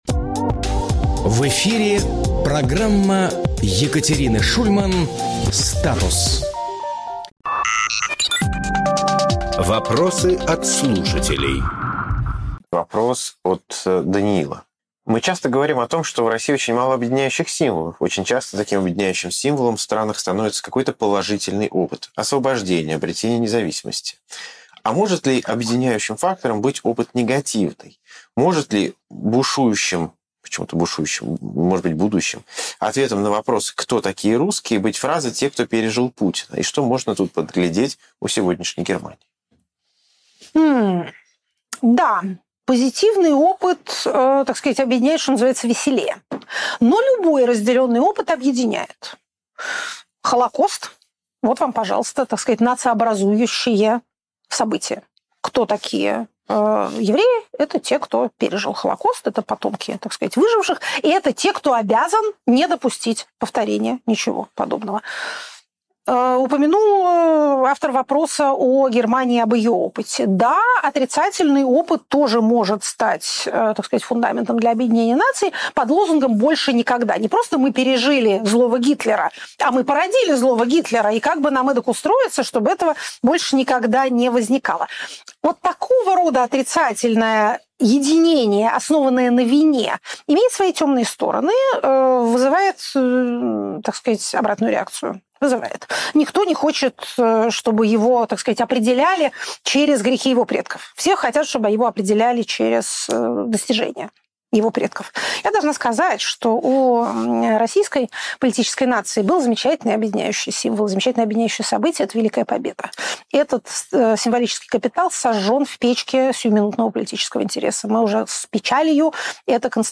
Фрагмент эфира от 14.04.26